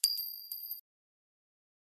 descargar sonido mp3 caida alfiler 1
pin-drop.mp3